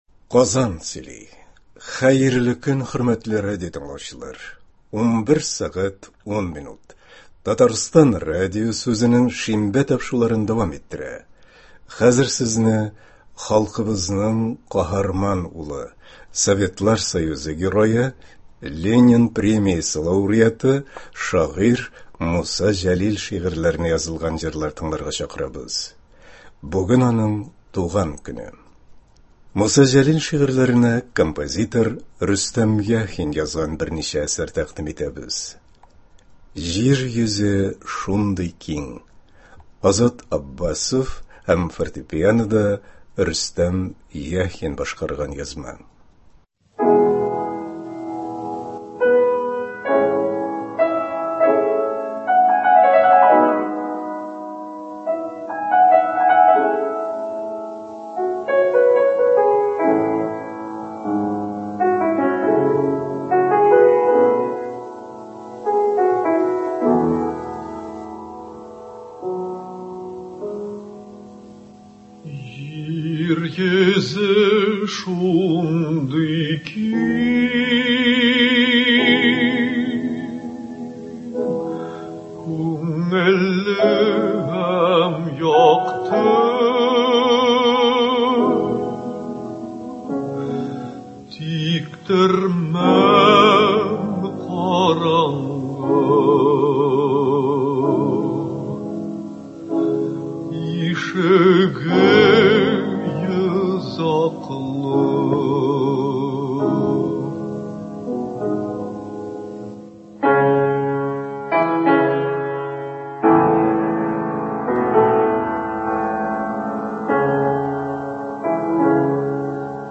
15 февраль — татар халкының каһарман улы, Советлар Союзы Герое, Ленин премиясе лауреаты, шагыйрь Муса Җәлилнең туган көне. Бүгенге концертыбызны аның шигырьләренә язылган җырлардан төзедек.